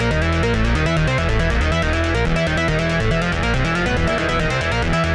Index of /musicradar/dystopian-drone-samples/Droney Arps/140bpm
DD_DroneyArp2_140-E.wav